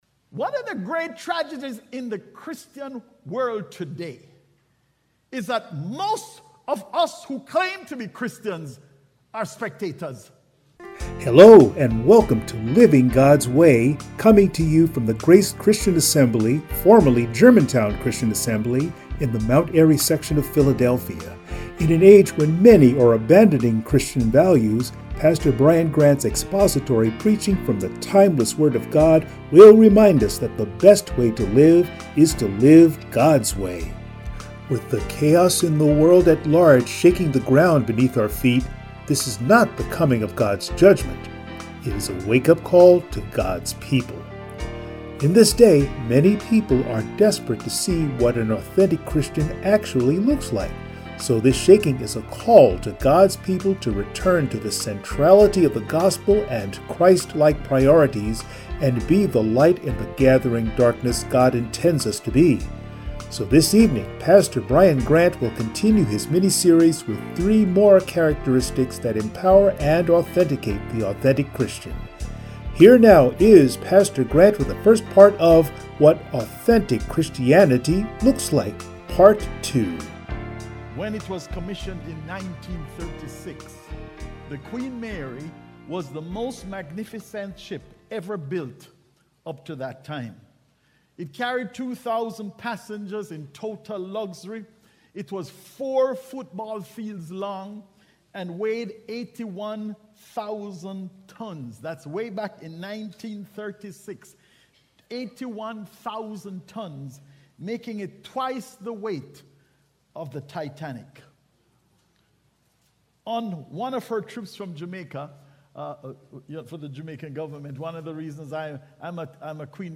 Passage: Matthew 15:1-20 Service Type: Sunday Morning